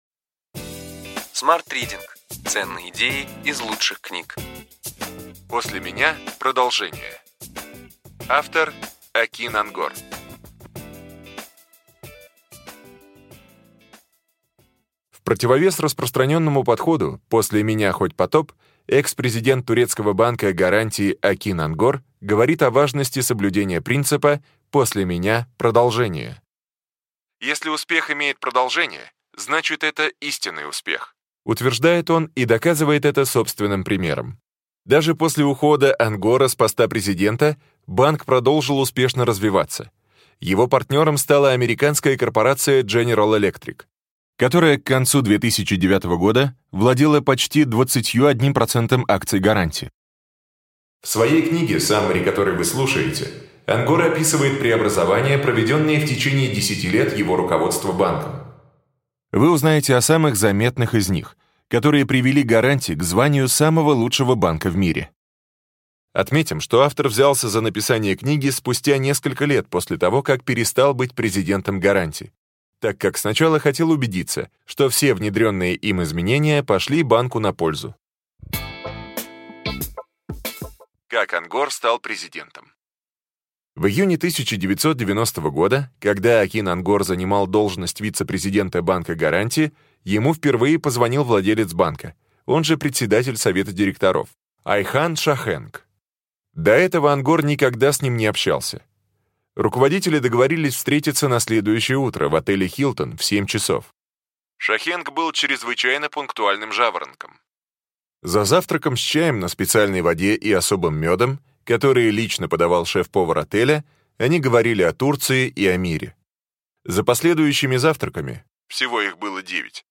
Аудиокнига Ключевые идеи книги: После меня – продолжение. Акин Онгор | Библиотека аудиокниг